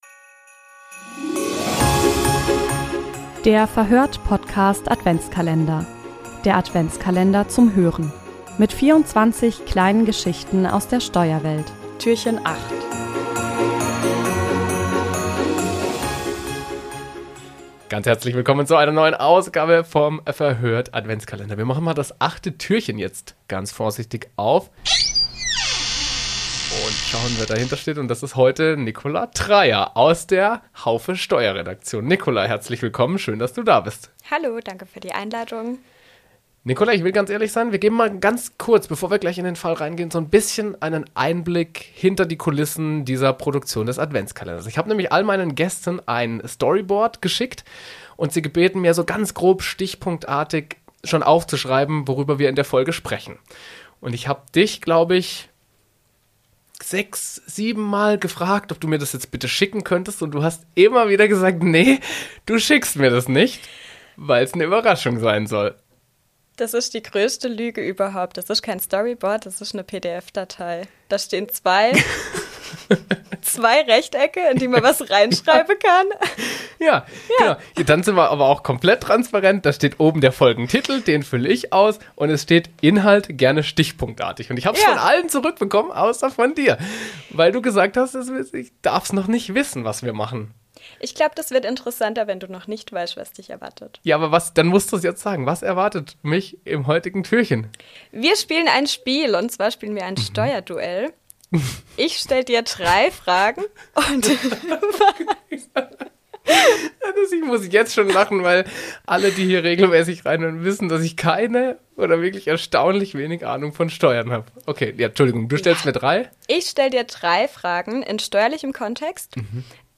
Im Verhör(t) Podcast-Adventskalender erzählen Steuerexpertinnen und Steuerexperten Geschichten und Anekdoten aus ihrem Alltag.